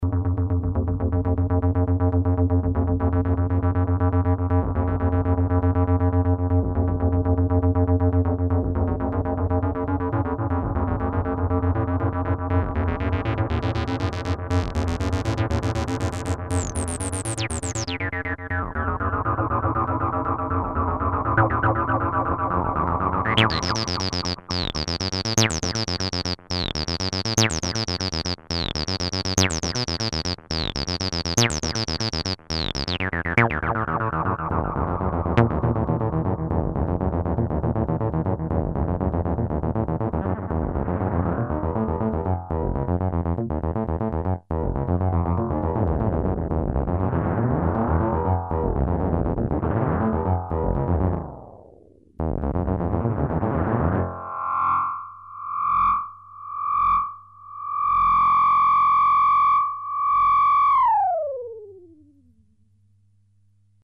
The original vintage analog delay which features a 300 ms time effect.
demo AUDIO DEMO
- warm warm sound
REVIEW "Not a long time delay and a bit low-fi bandwidth but warm and "round" sounds make this a great analog delay at reasonable price"